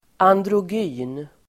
Ladda ner uttalet
Uttal: [andråj'y:n]